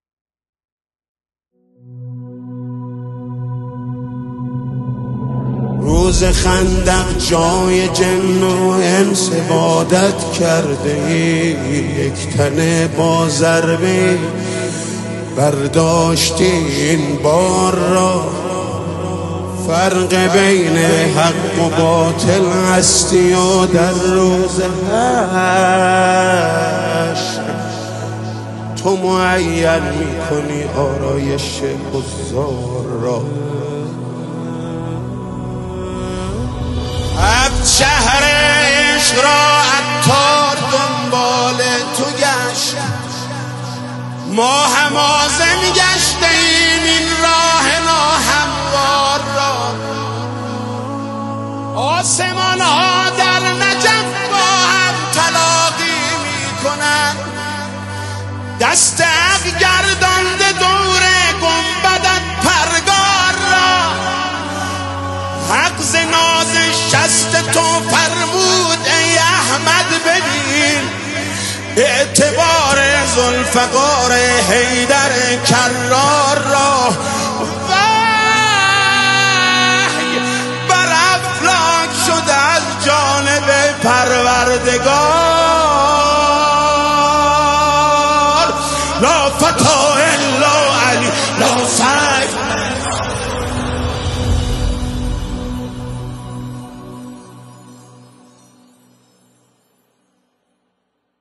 ویژه سالروز میلاد مسعود امام علی علیه السلام